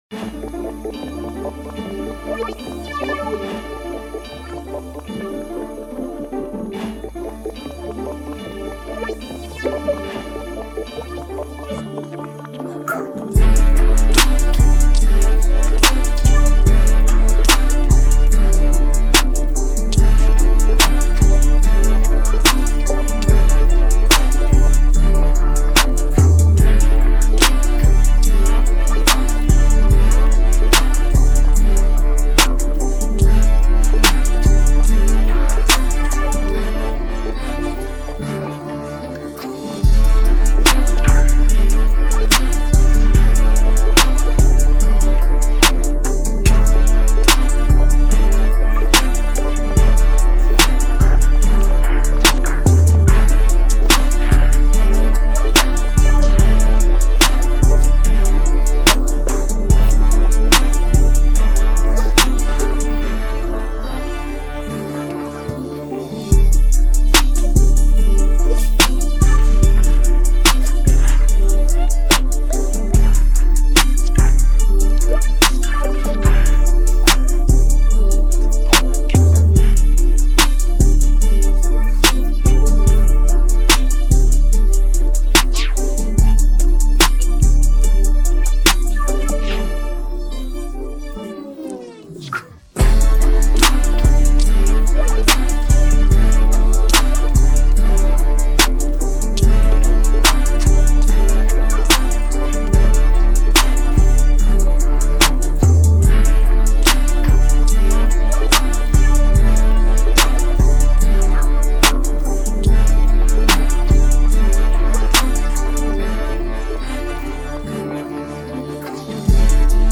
Here's the official instrumental
Drill Instrumental